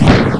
1 channel
STOMP.mp3